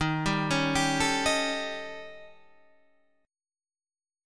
D# 7th Flat 5th